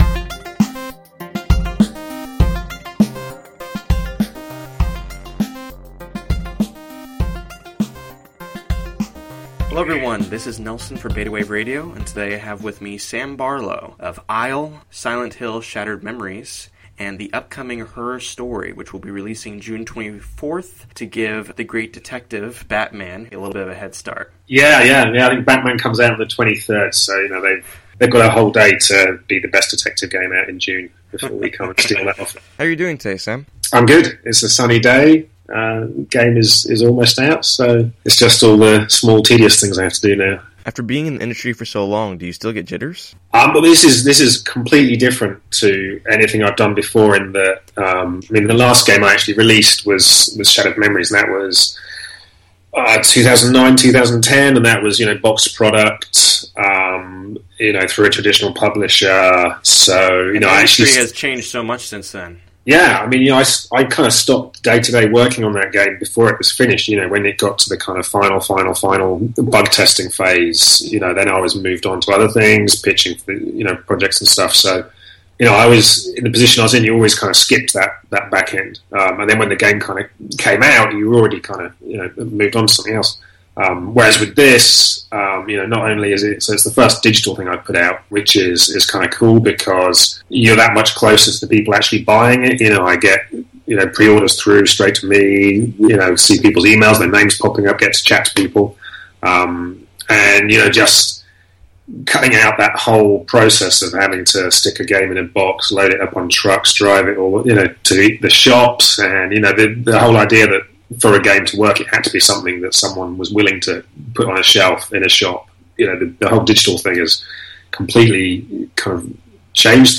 Ephemeral–A Conversation with Sam Barlow (Developer of Her Story)
ephemeral-a-conversation-with-sam-barlow.mp3